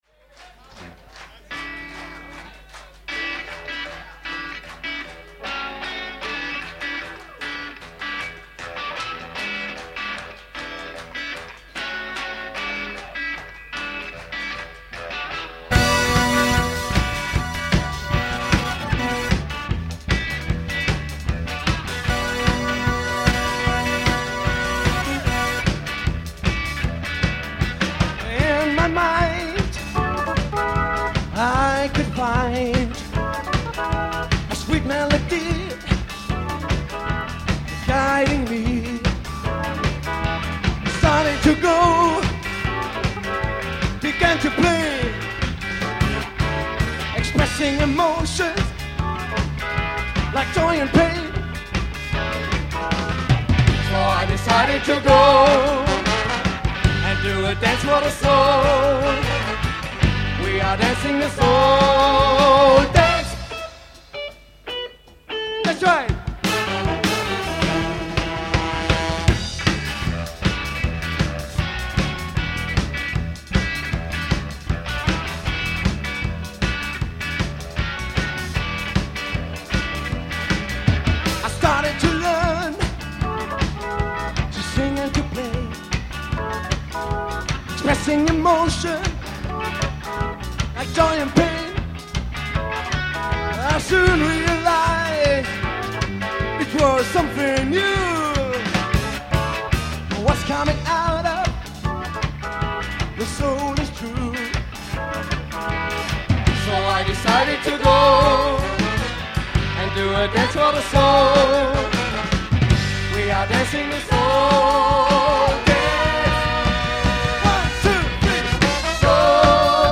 Tapes - Mitschnitte (alte Bänder ohne Studiotechnik)
Freiburg - Cafe Atlantik 30.11.1987
Git.+Vocal
Sax.
Trombone
Trompete
Bass
Drums